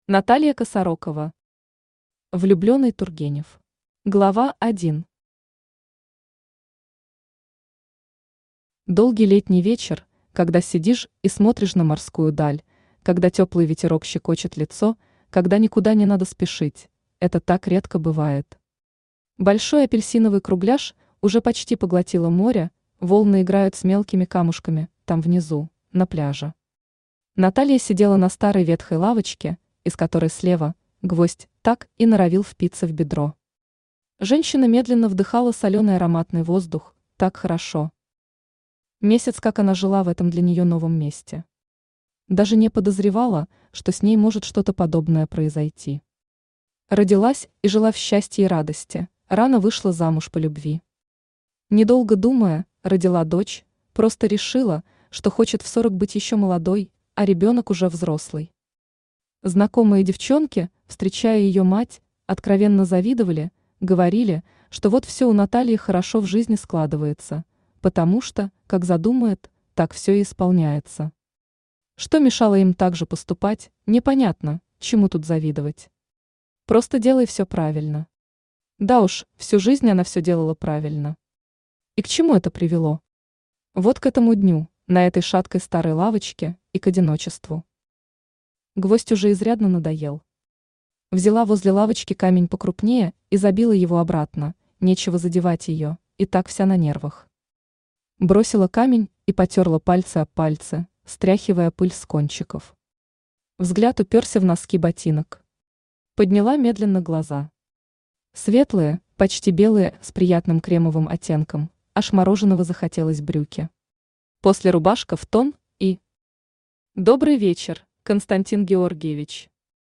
Аудиокнига Влюбленный Тургенев | Библиотека аудиокниг
Aудиокнига Влюбленный Тургенев Автор Наталья Владимировна Косарокова Читает аудиокнигу Авточтец ЛитРес.